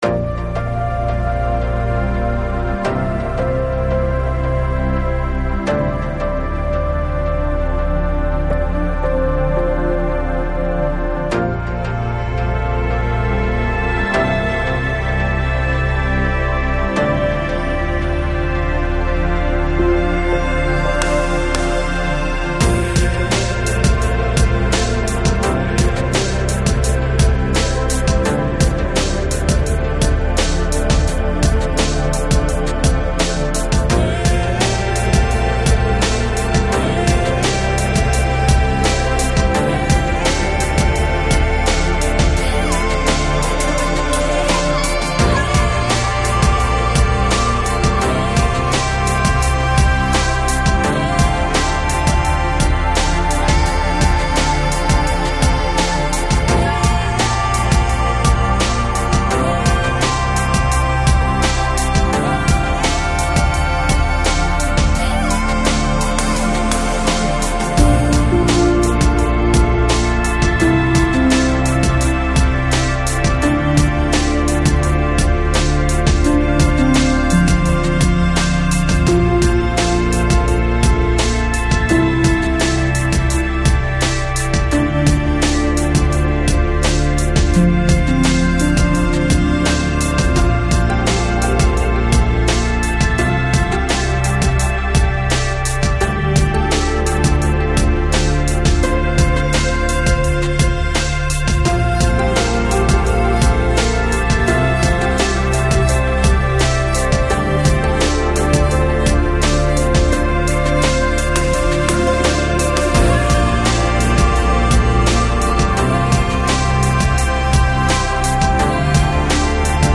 Chill Out, Lounge, Downtempo, Enigmatic